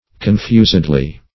Confusedly \Con*fus"ed*ly\, adv.